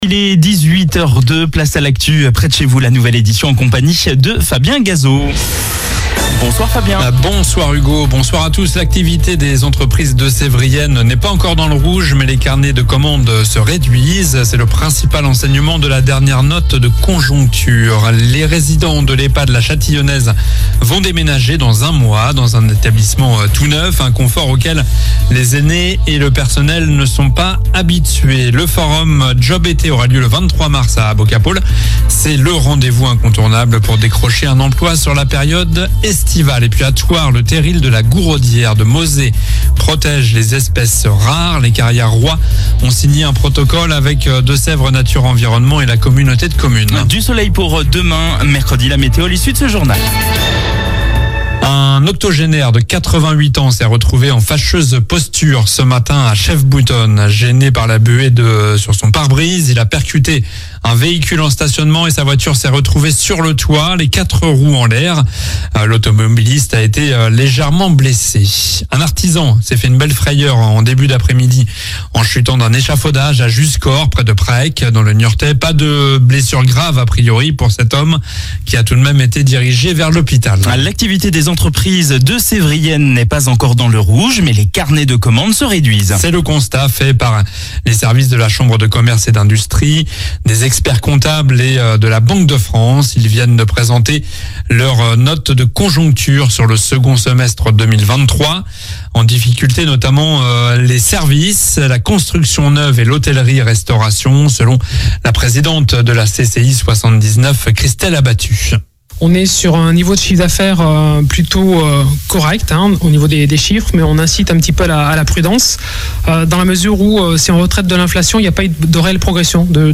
Journal du mardi 12 mars (soir)